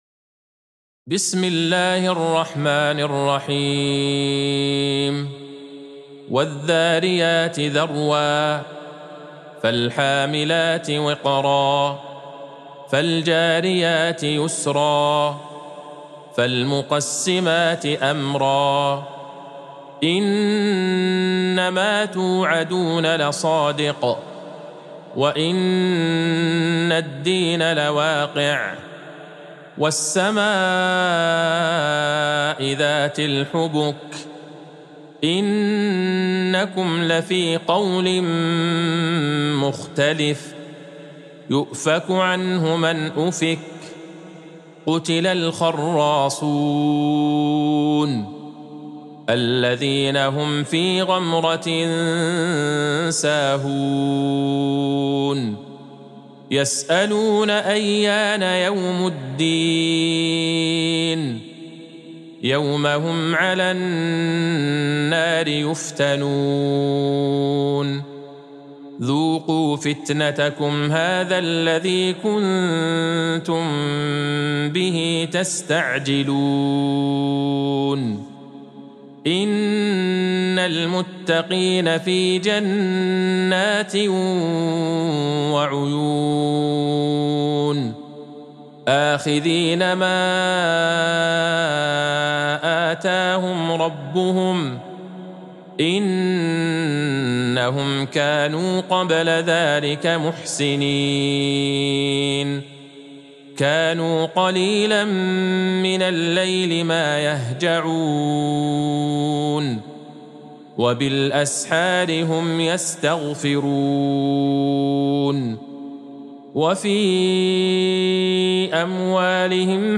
سورة الذاريات Surat Al-Dharyat | مصحف المقارئ القرآنية > الختمة المرتلة